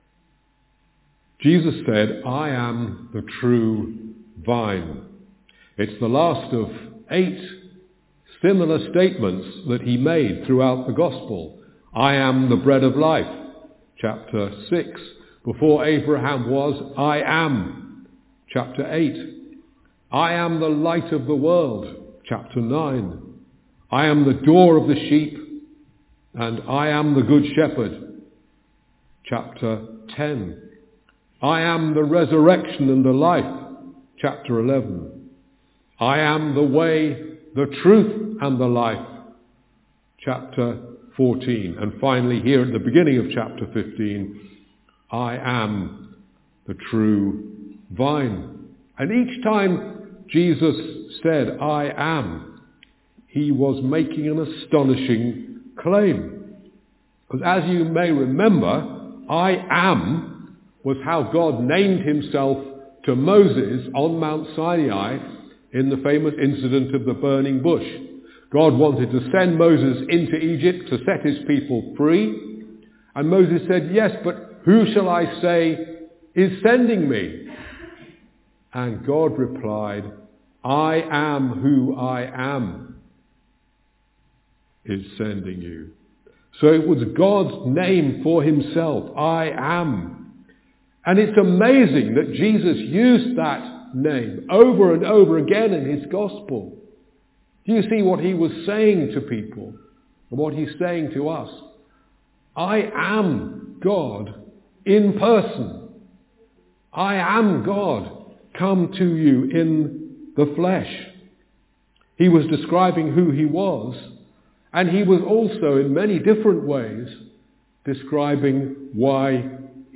Talk only; prayer at the end of the talk